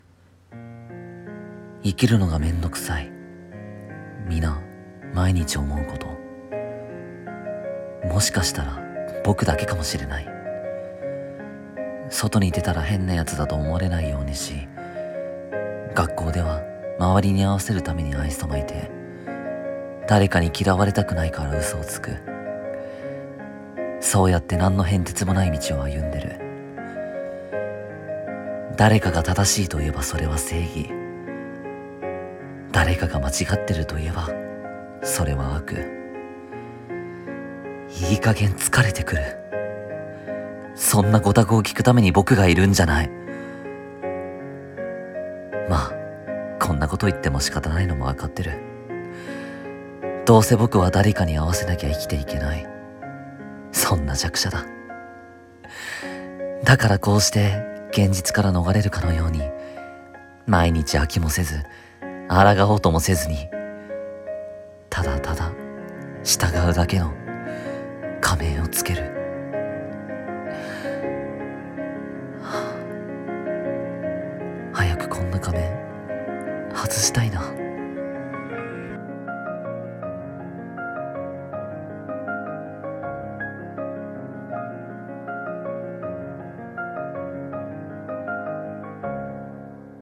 【一人声劇】「仮面」